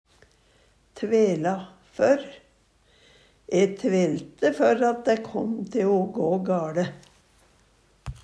tvela før - Numedalsmål (en-US)
DIALEKTORD PÅ NORMERT NORSK tvela før tru kanskje at Eksempel på bruk E tvelte før at dæ kåm te o gå gaLe.